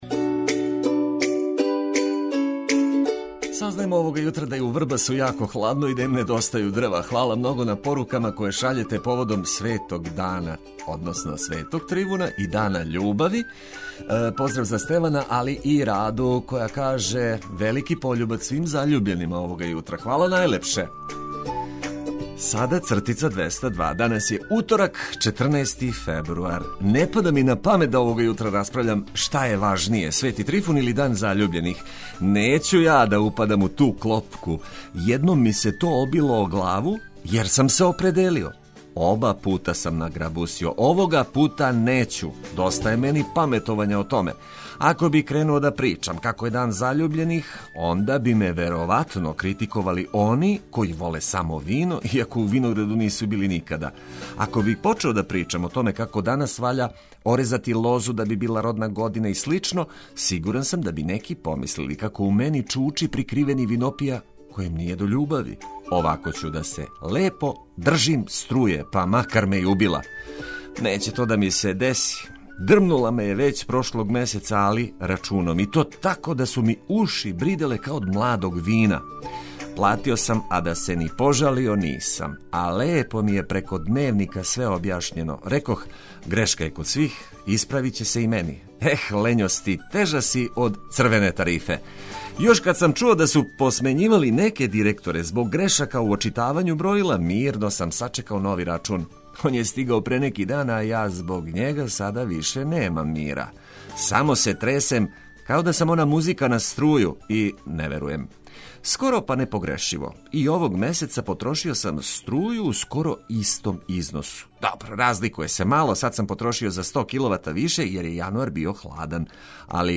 Током јутра најбитније информације прошаране одличном музиком за буђење.